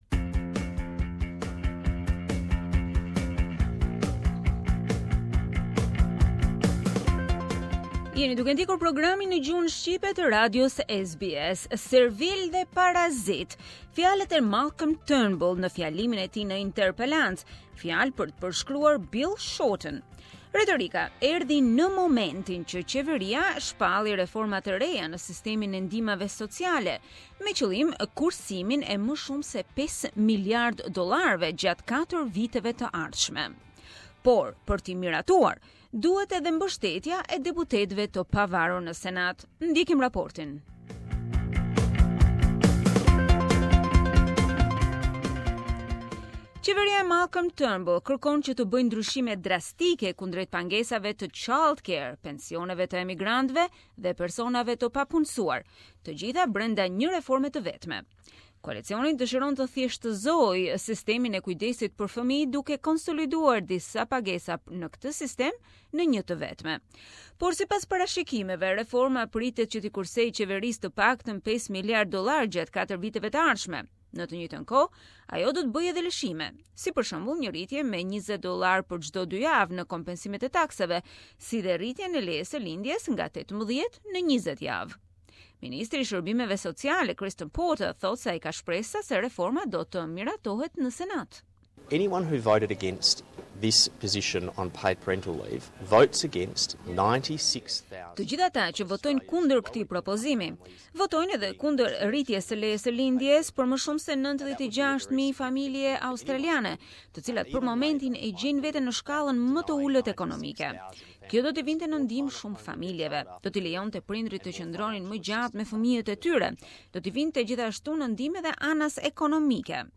The words of Malcolm Turnbull in his blistering attack on Bill Shorten during Question Time in parliament. The exchange came as the federal government announced sweeping welfare reforms aimed at saving just over $5 billion dollars over the next four years.